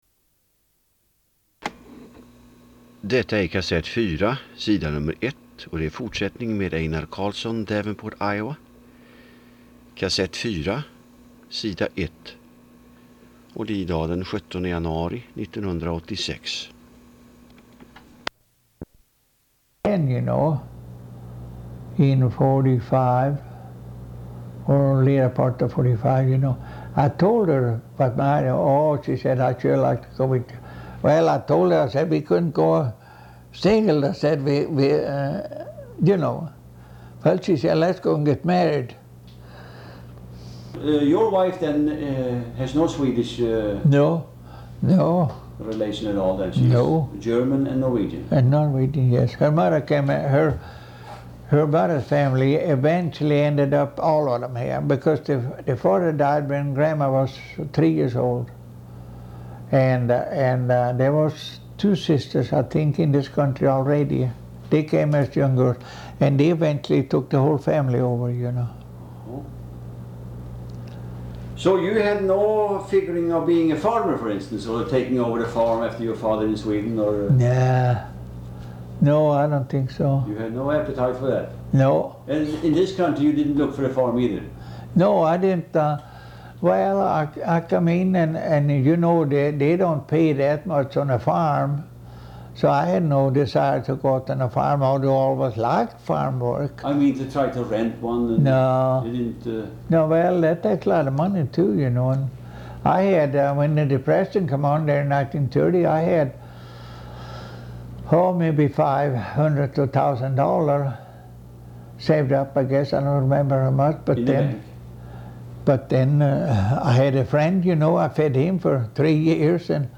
Intervju.